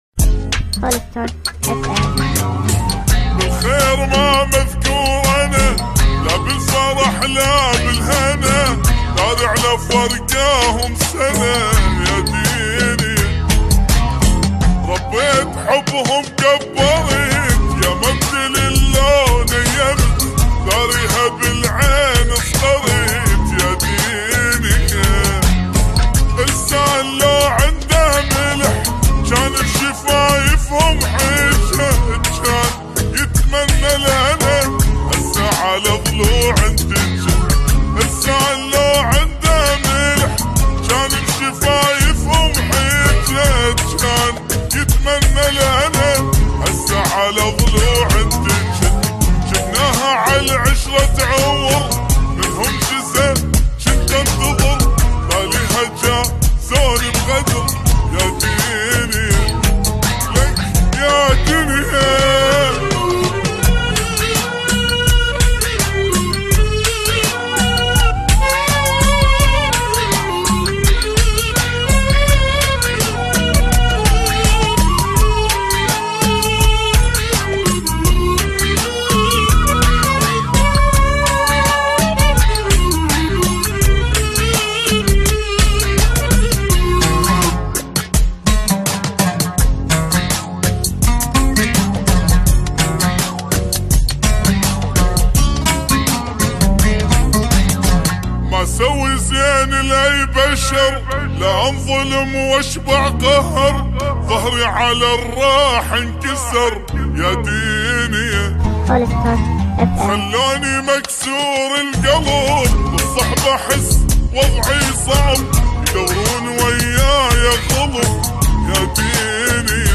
عراقي بطيء